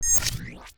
UIMvmt_Futuristic Power PickUp 02.wav